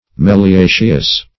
Search Result for " meliaceous" : The Collaborative International Dictionary of English v.0.48: Meliaceous \Me`li*a"ceous\, a. (Bot.) Pertaining to a natural order ( Meliacae ) of plants of which the genus Melia is the type.
meliaceous.mp3